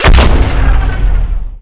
fire_plasma4.wav